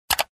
Звуки клика мышкой
На этой странице собраны звуки кликов компьютерной мыши — от одиночных щелчков до быстрых последовательностей.